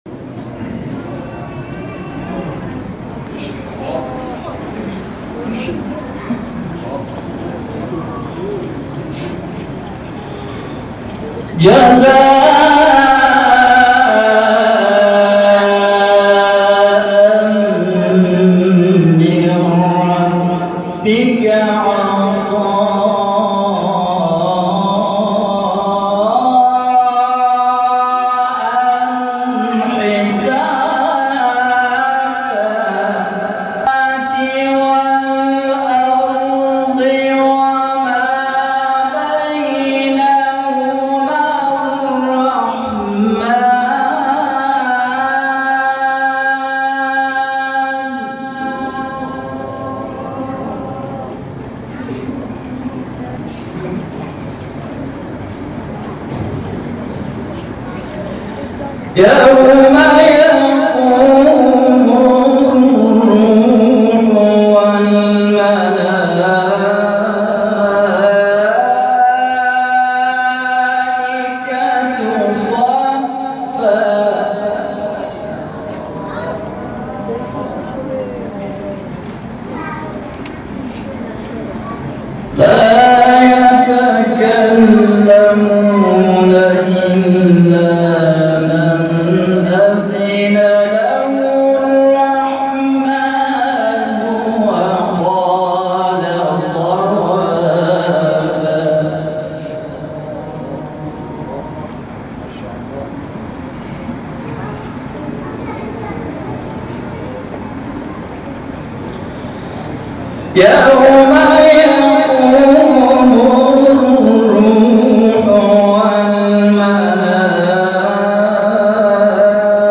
محفل انس با قرآن روستای سالم آباد